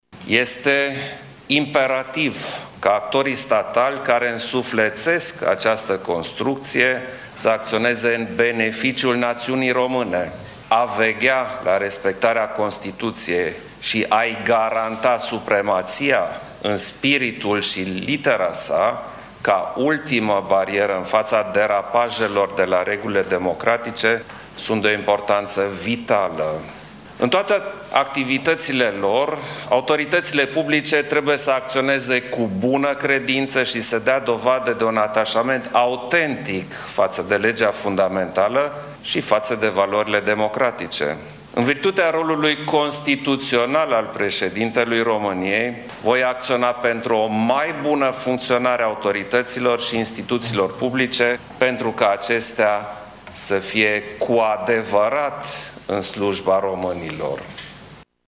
Pentru cel de-al doilea mandate Klaus Iohannis a arătat că va veghea în continuare la respectarea Constituției și va susține valorile staului de drept și parcursul european al României: